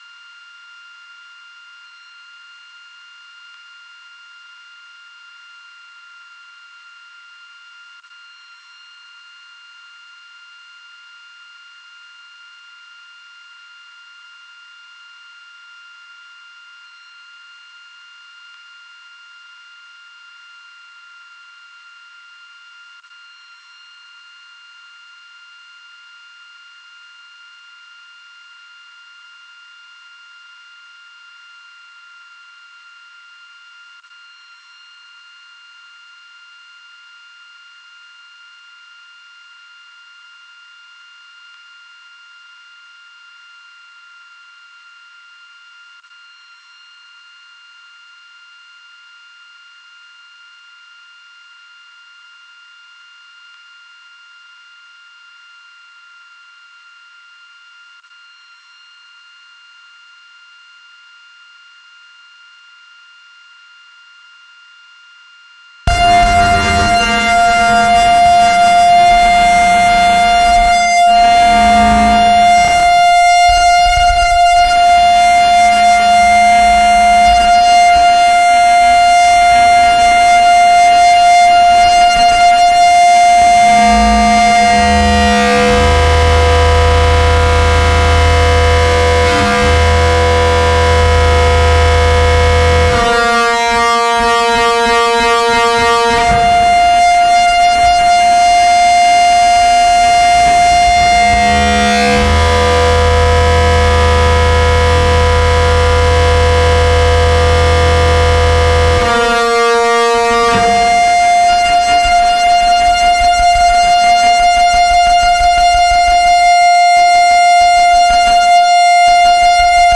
live at mayhem